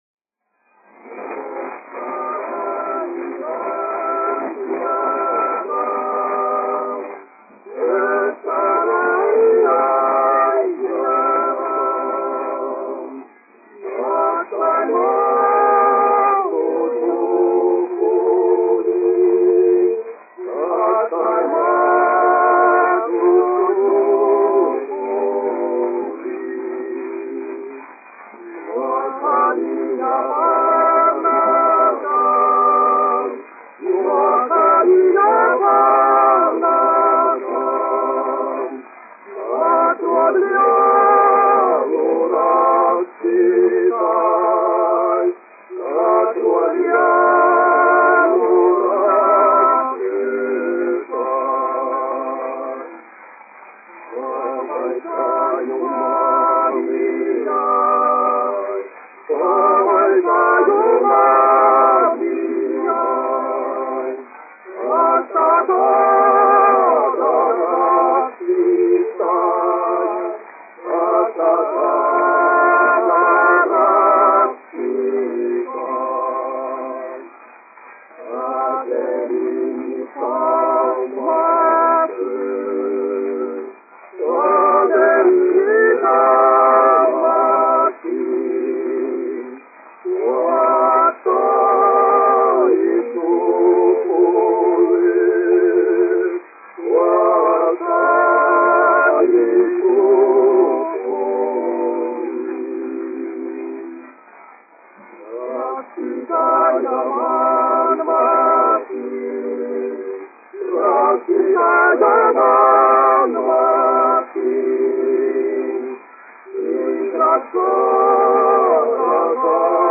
Jaunā Rīgas teātra vīru kvartets, izpildītājs
1 skpl. : analogs, 78 apgr/min, mono ; 25 cm
Latviešu tautasdziesmas
Vokālie kvarteti
Latvijas vēsturiskie šellaka skaņuplašu ieraksti (Kolekcija)